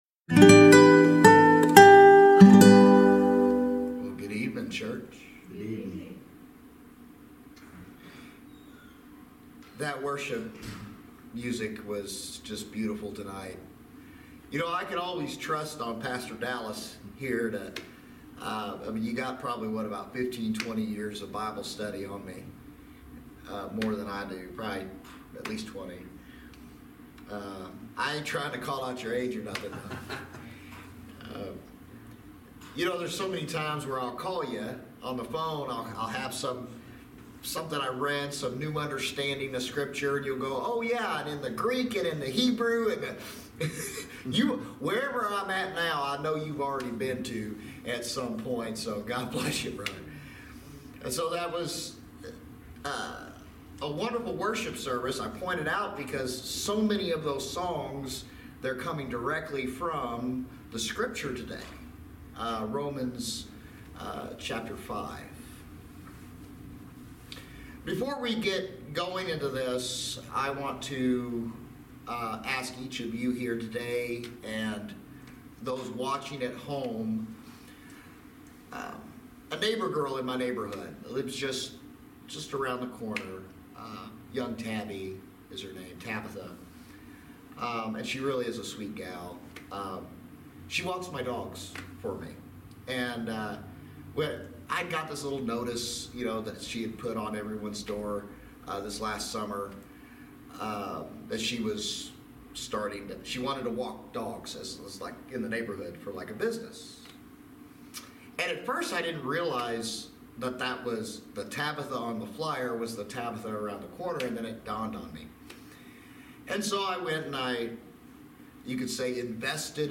Passage: Romans 5:1-21 Service Type: Thirsty Thursday Midweek Teaching